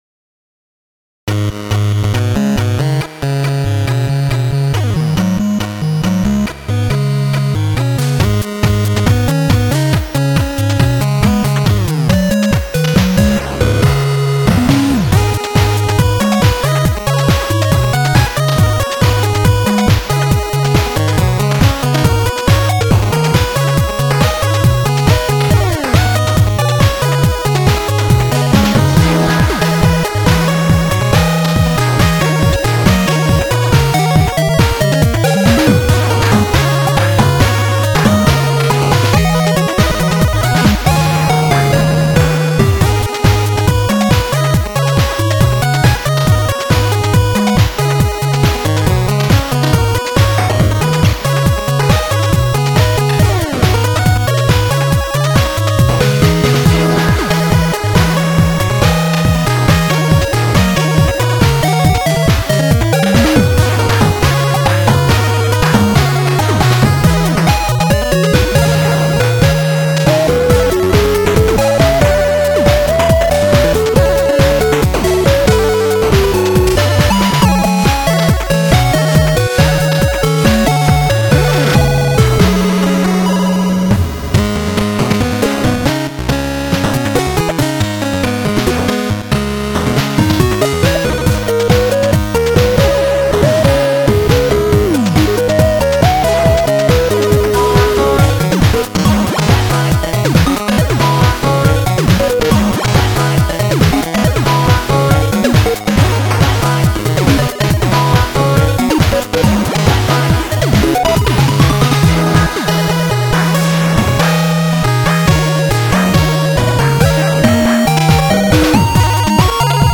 NSF は、ファミコン演奏用のデータです。
(2A03)